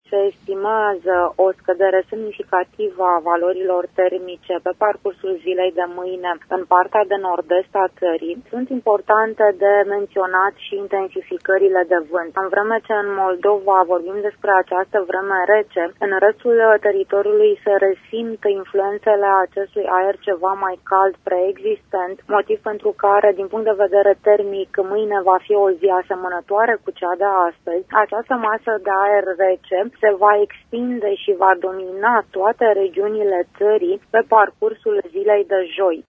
Începând de mâine va intra prin nord estul teritoriului o masă de aer polar ce se va extinde treptat în toată ţara. Ne oferă detalii meteorologul de serviciu